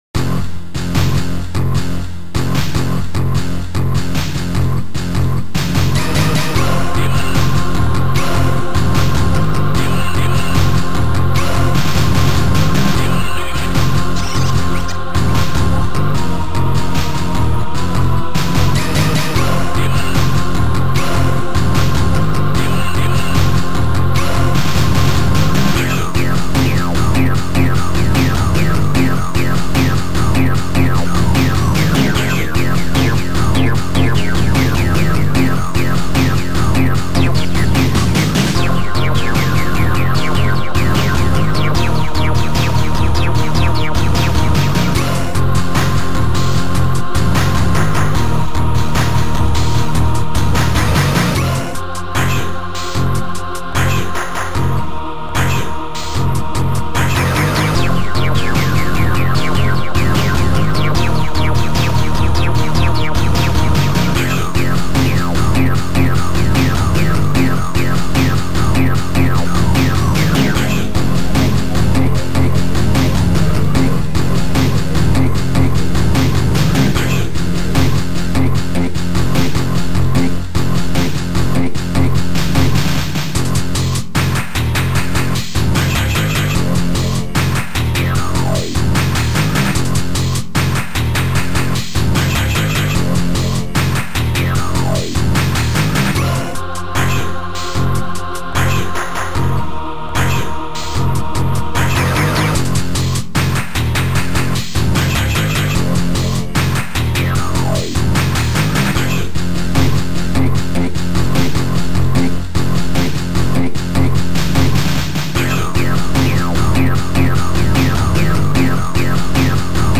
Sound Format: Noisetracker/Protracker
Sound Style: Synth Disco / Rock